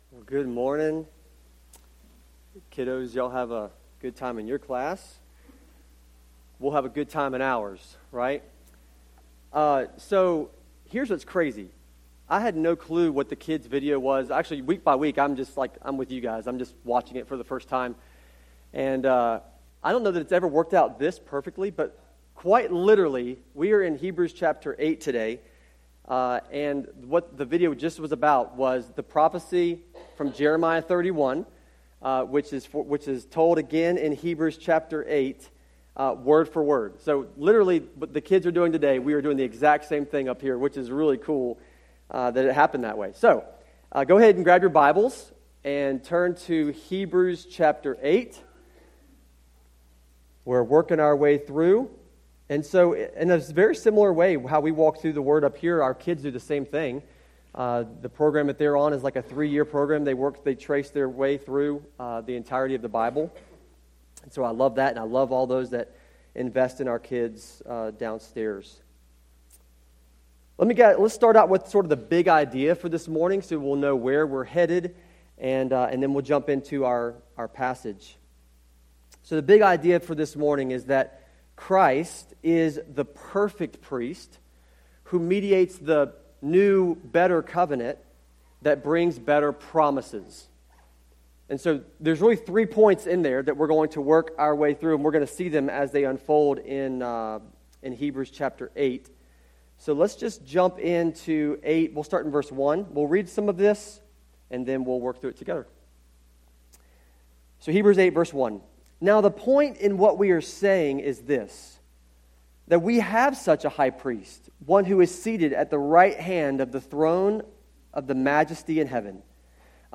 sermon-audio-trimmed-2.mp3